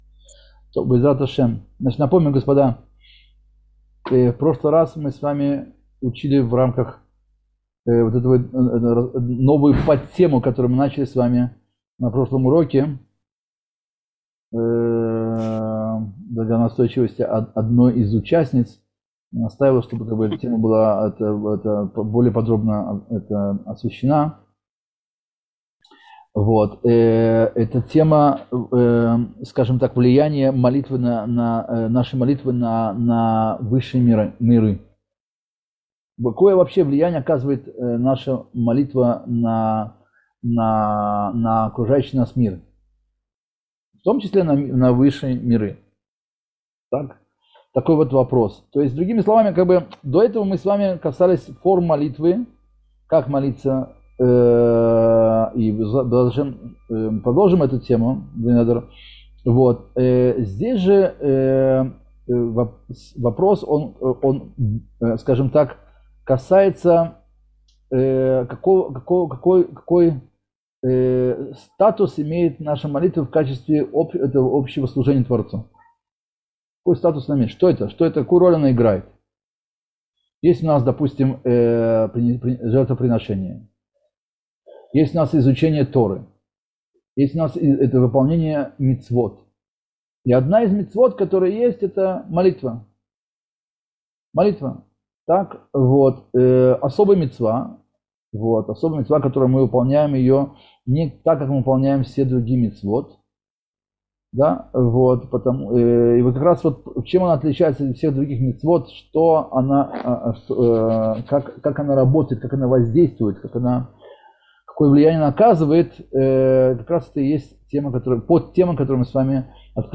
Цикл уроков по недавно вышедшей в свет книге рава Шимшона Давида Пинкуса «Врата в мир молитвы»